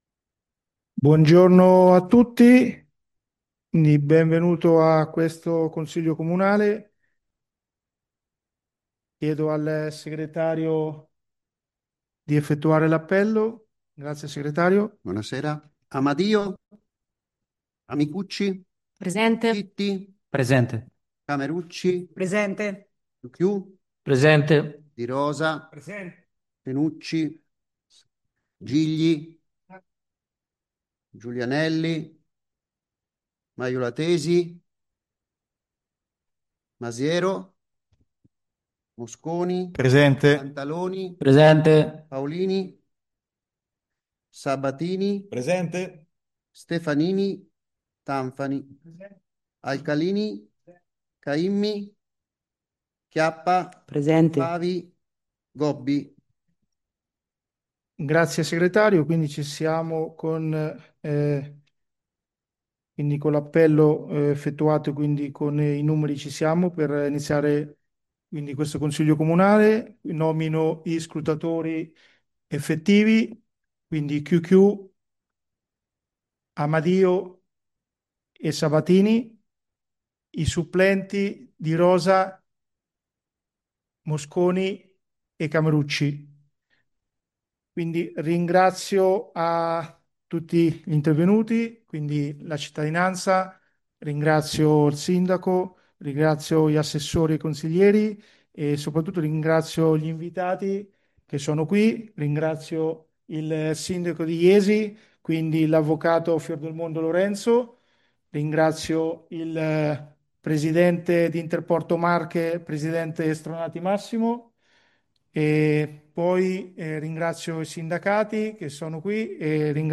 registrazione consiglio 12.03.2026
Seduta aperta del 12 marzo 2026